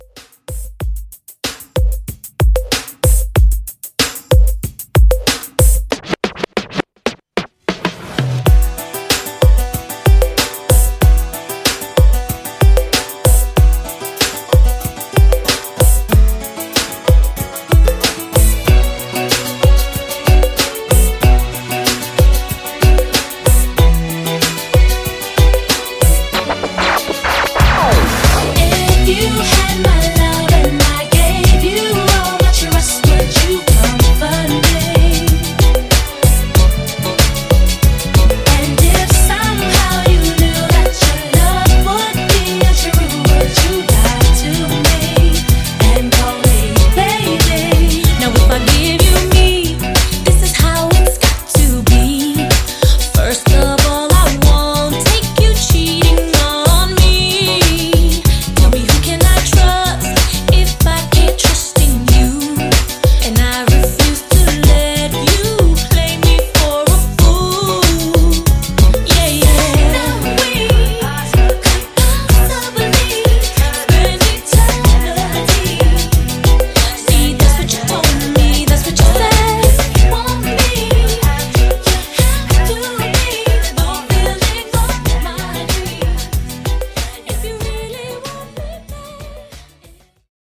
90’s Soul Re-Drum)Date Added